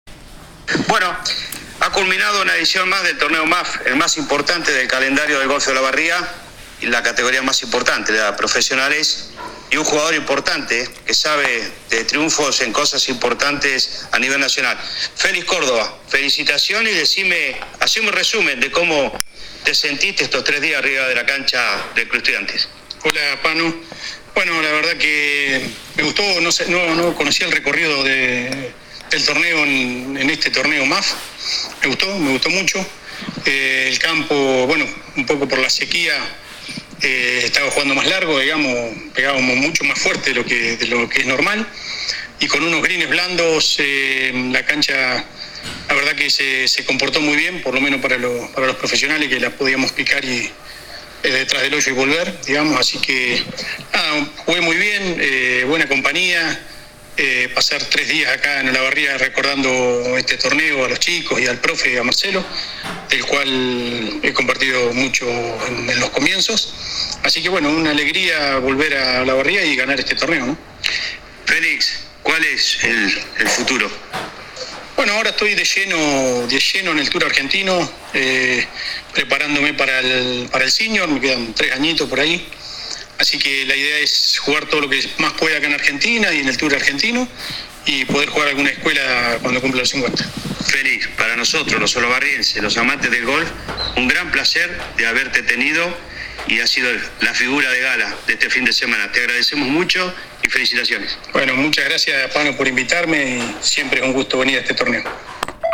AUDIO DE LA ENTREVISTA https